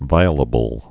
(vīə-lə-bəl)